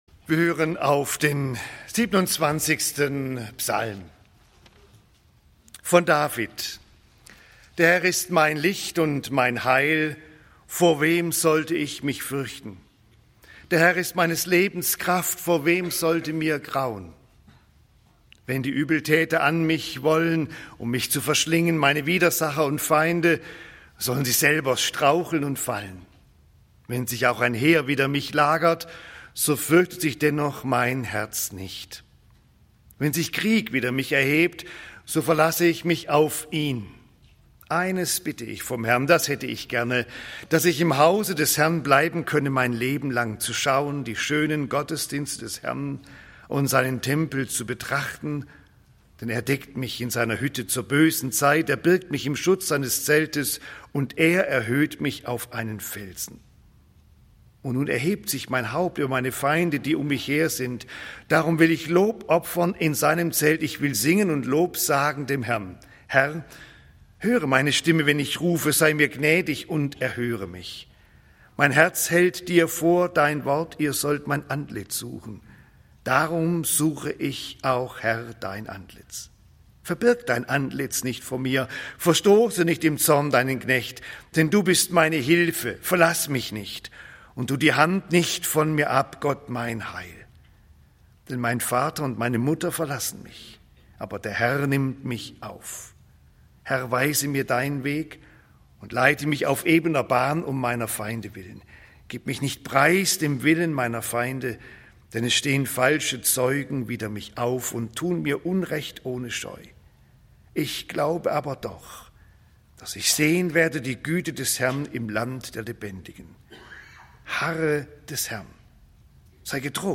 Der Herr ist mein Licht und mein Heil (Ps. 27) - Gottesdienst